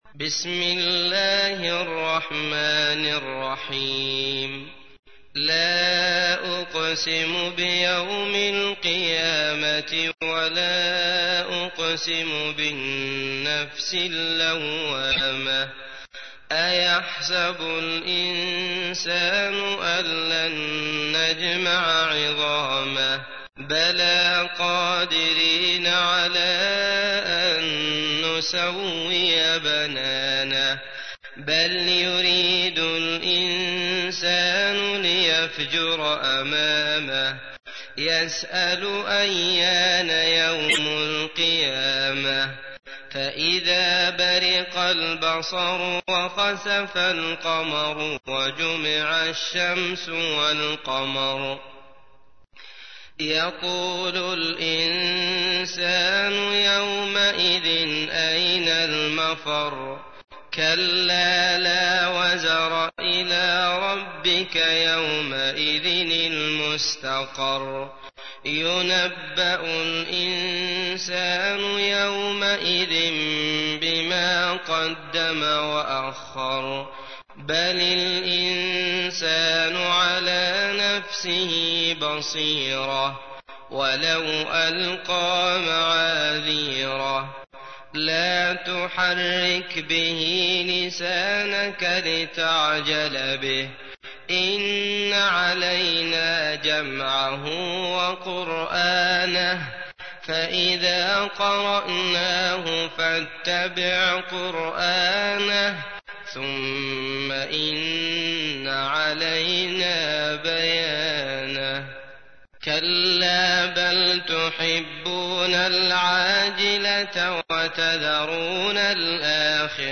تحميل : 75. سورة القيامة / القارئ عبد الله المطرود / القرآن الكريم / موقع يا حسين